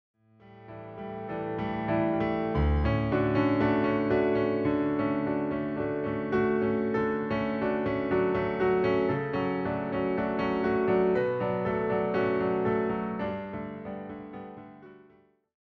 all performed as solo piano arrangements.